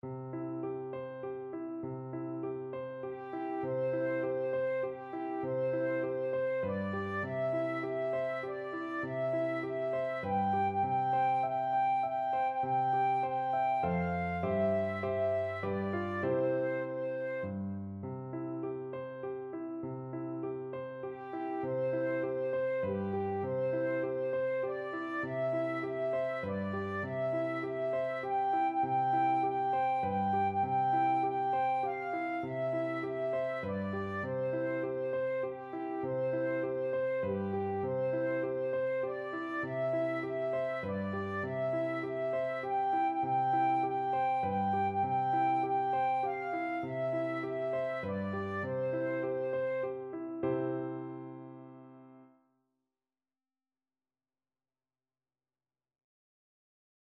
Flute
3/4 (View more 3/4 Music)
Gently =c.100
C major (Sounding Pitch) (View more C major Music for Flute )
G5-G6
fires_burning_FL.mp3